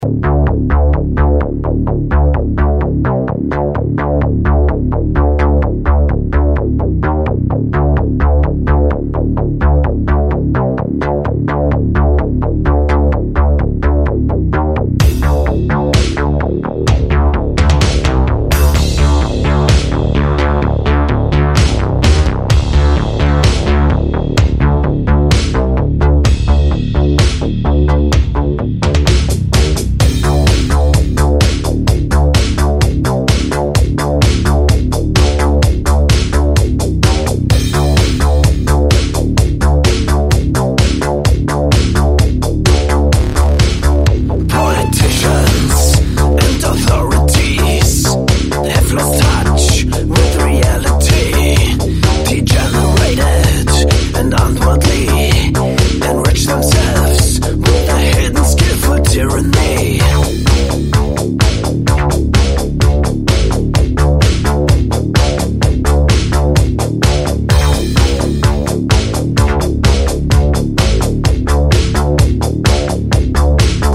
• Качество: 128, Stereo
ритмичные
без слов
Electronica
загадочные
Интересный электронный рингтон